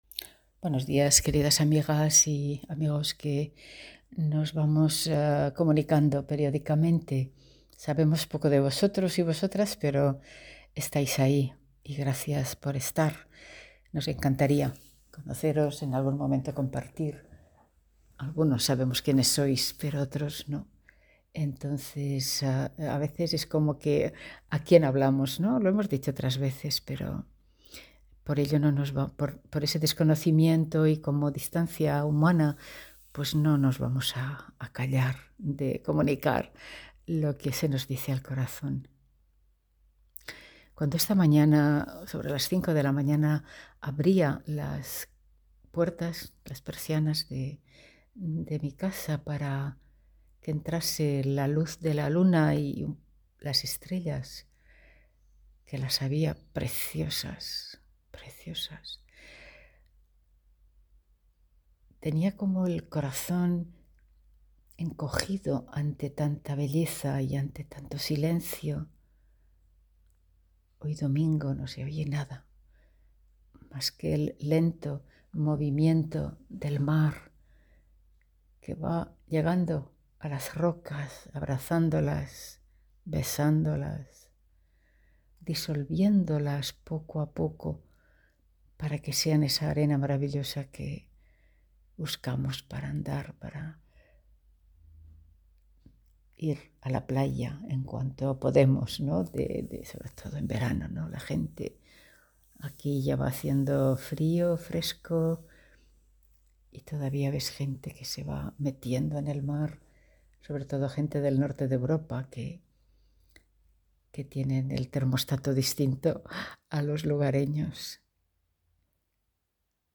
Generado por IA